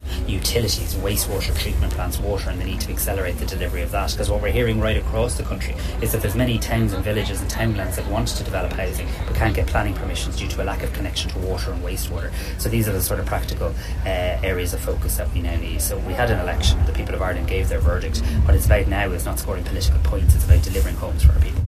He rejects opposition claims that voters were misled – saying what the public needs is more action on housing and less political point-scoring: